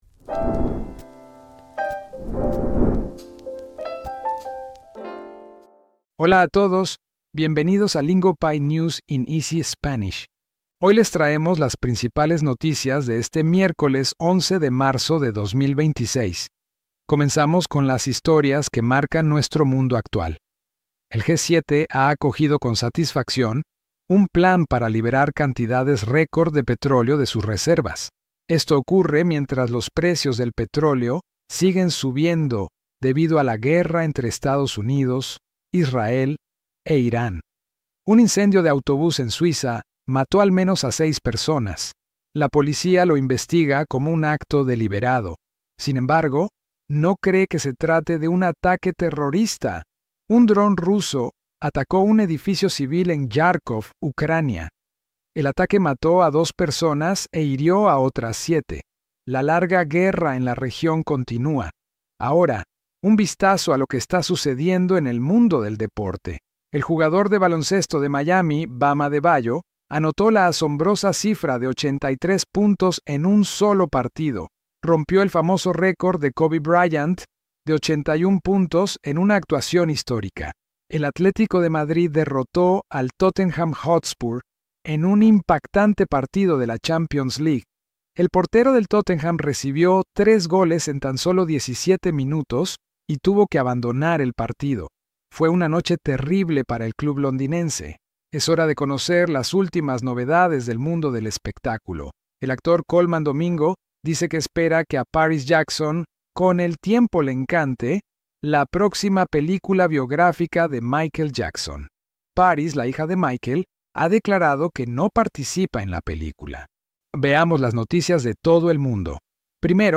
Lingopie’s News in Easy Spanish gives you real world Spanish listening practice through today’s biggest global headlines. We keep the Spanish clear and beginner-friendly, so you can follow along without constantly rewinding.